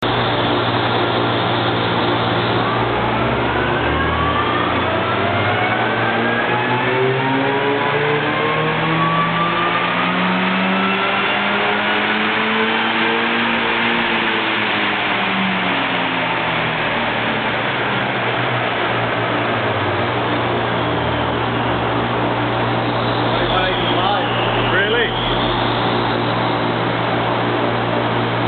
The noise during the run is horrifying, with fans to keep the engine somewhat cool, the dyno and of course the noise of the engine being rev’ed up to its max!
Dyno runs make a lot of noise!